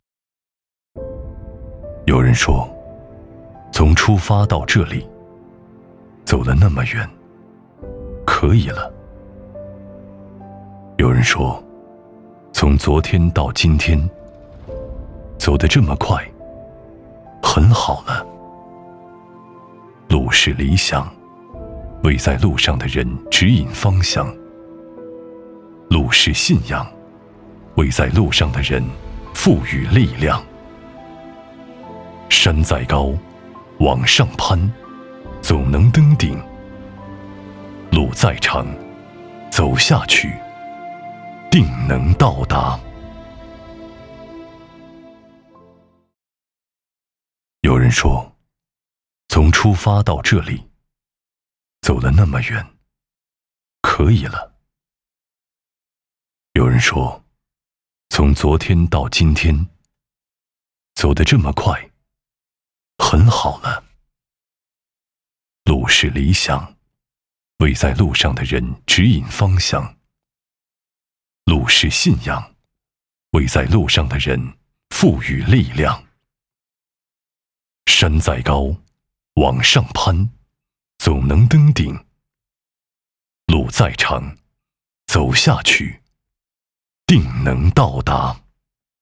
微电影配音
男国330_其他_微电影_有人说.mp3